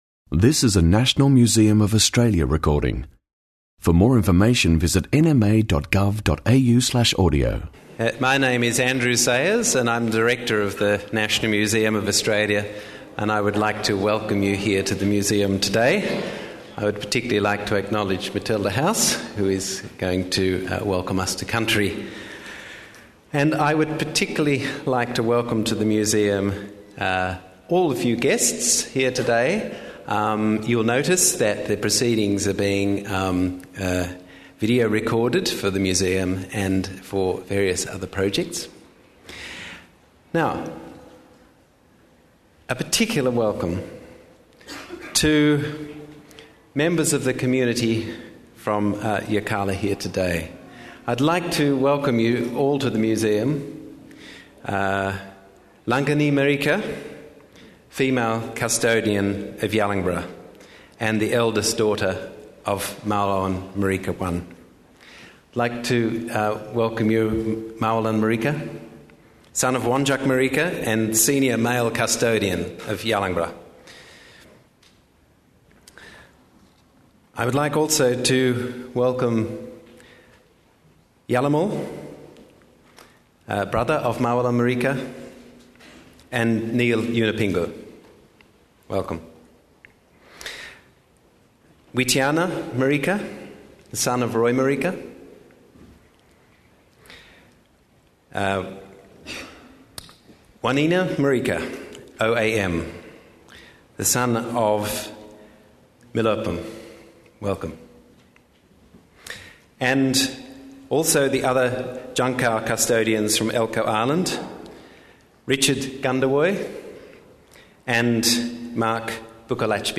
Yalangbara: Art of the Djang’kawu: exhibition launch | National Museum of Australia